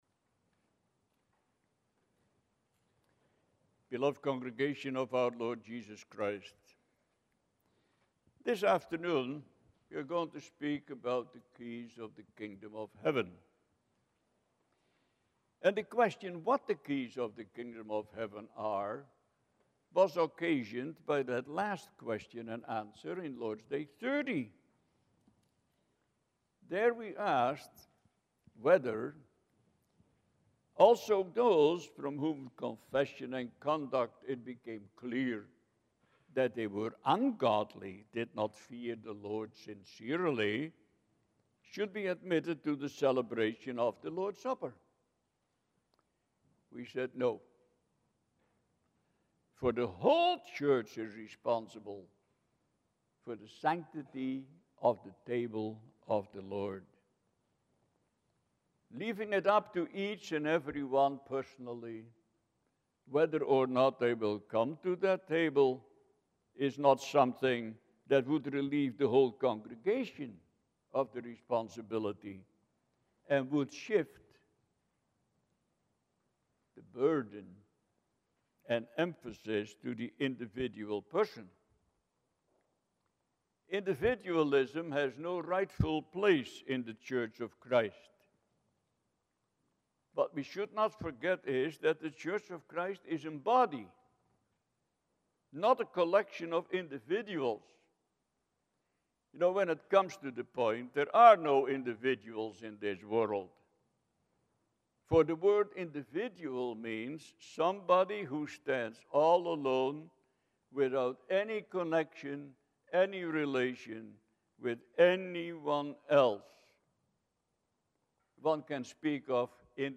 Service Type: Sunday Afternoon